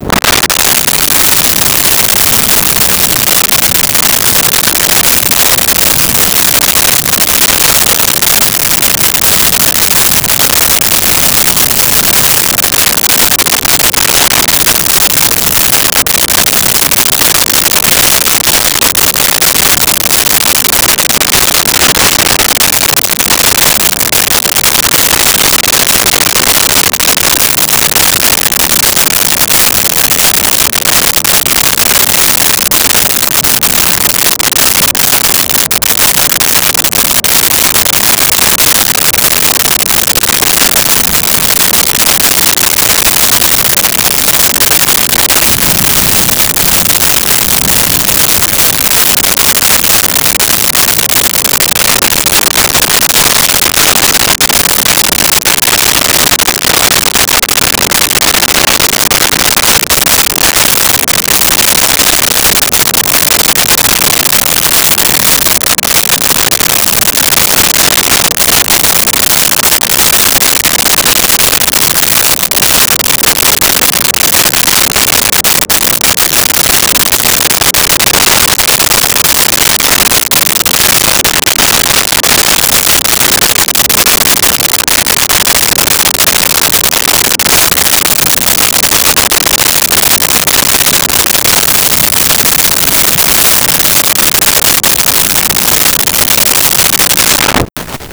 Wind Strong
Wind Strong.wav